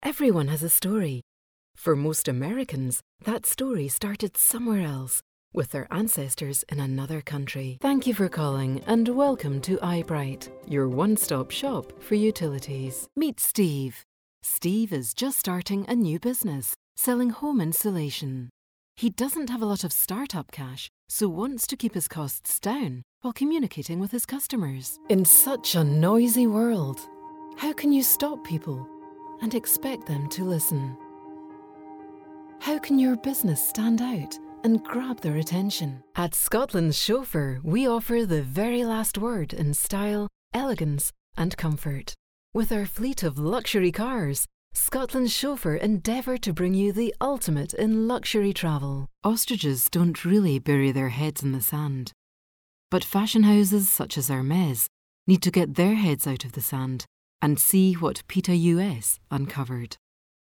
Commercial Showreel